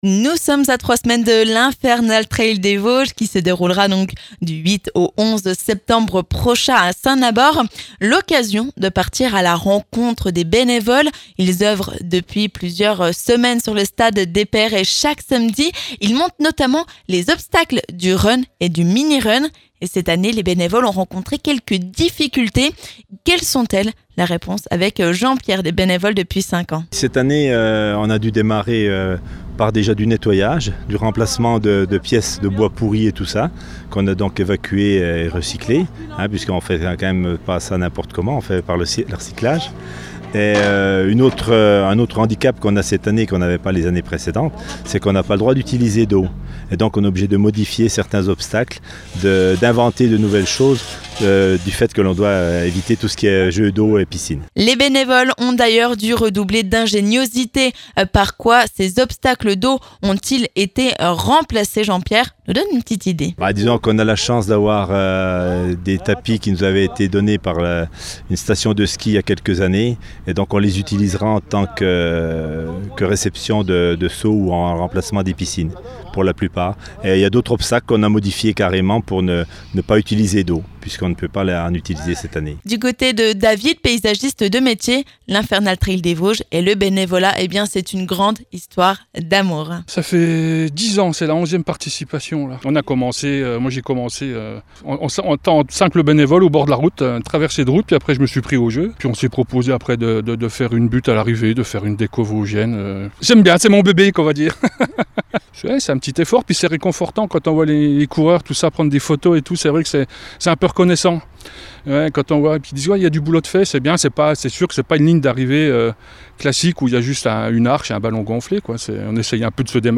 %%La rédaction de Vosges FM vous propose l'ensemble de ces reportages dans les Vosges%%
A trois semaines de l'Infernal Trail des Vosges, nous sommes partis à la rencontre de deux bénévoles qui s'activent chaque samedi, sur le Stade des Perrey à Saint-Nabord !